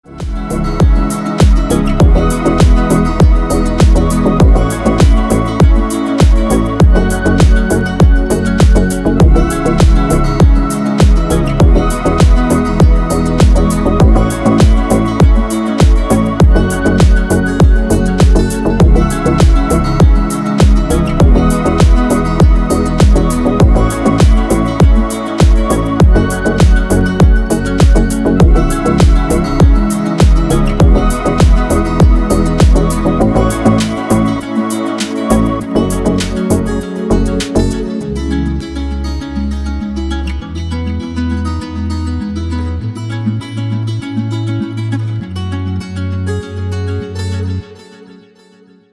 Нарезки спокойных треков
• Песня: Рингтон, нарезка